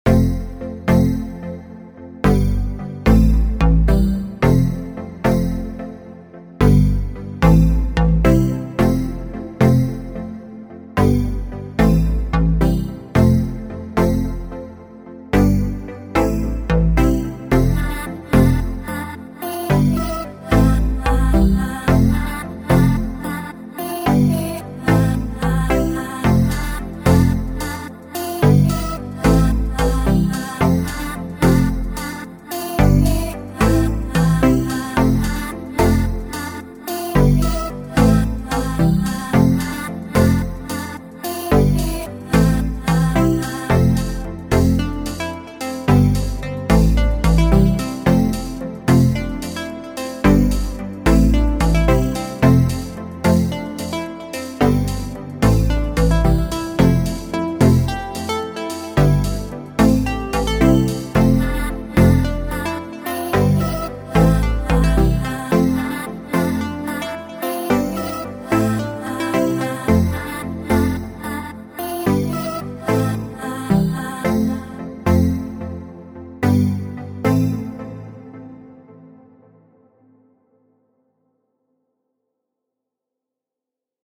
with Vocal Chops